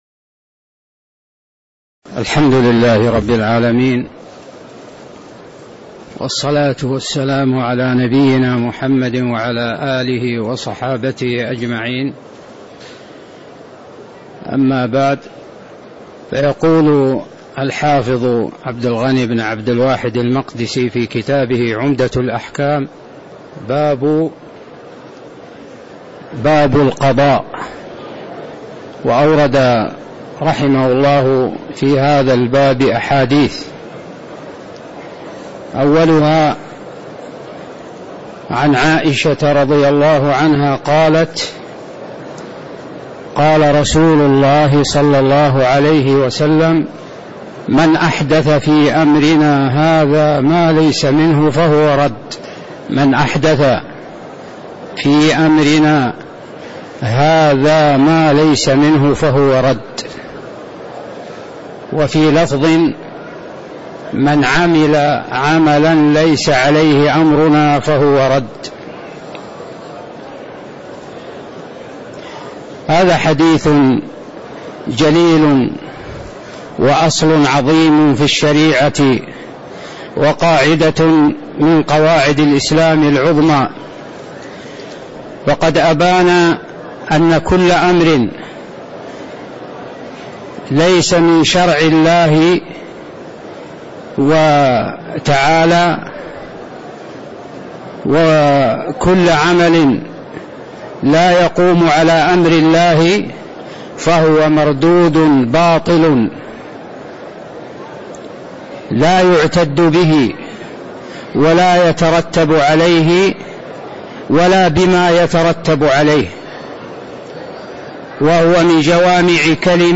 تاريخ النشر ٥ رجب ١٤٣٧ هـ المكان: المسجد النبوي الشيخ